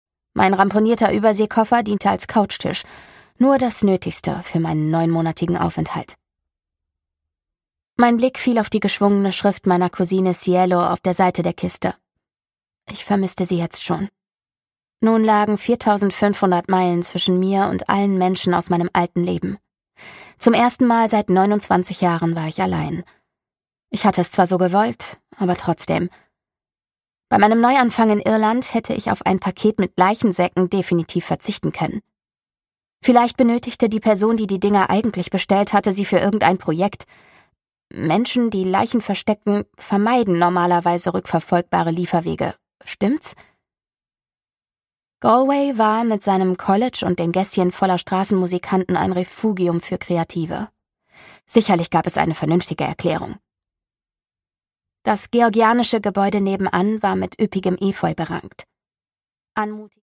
MP3 Hörbuch-Download